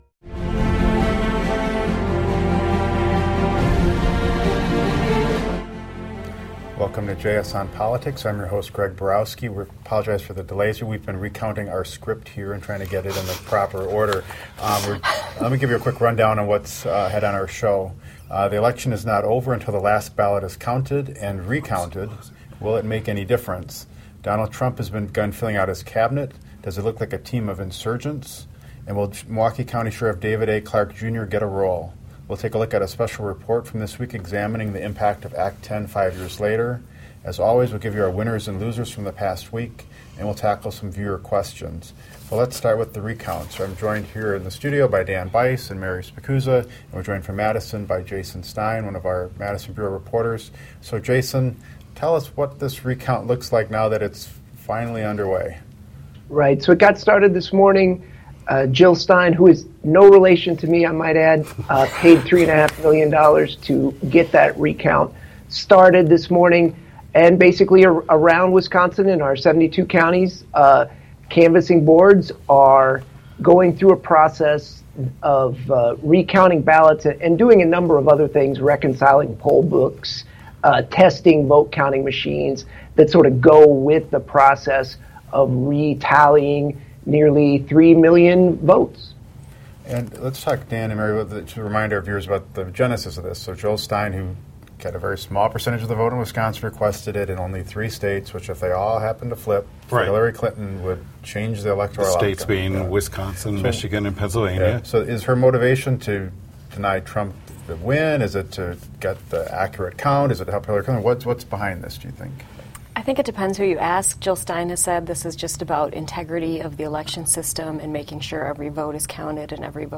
In this episode, our panel discussed if the recount in Wisconsin will make a difference, Trump filling out his cabinet and what the future holds for Sheriff David A. Clarke Jr. They also take a look at Act 10 after five years, pick winners and losers and answer viewer questions.